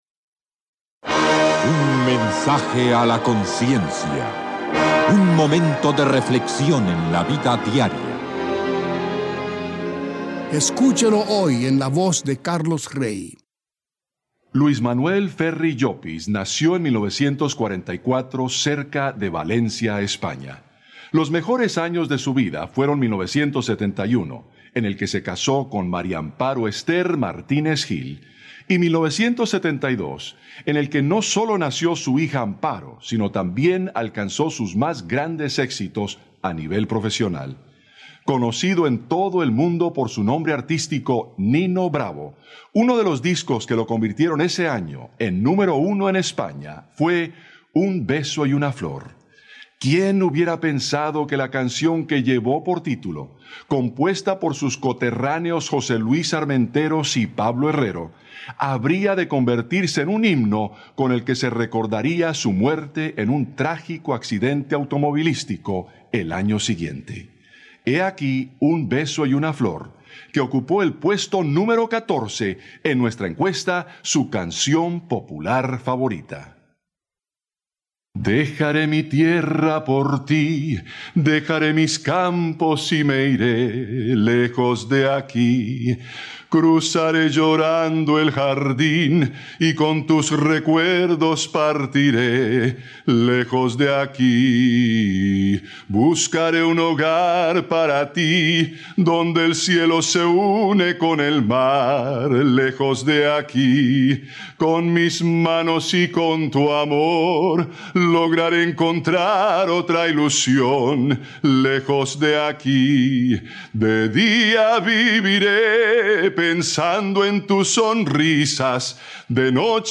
Mensajes a la Conciencia: mensajes breves a modo de par�bolas cristianas en texto, audio y video.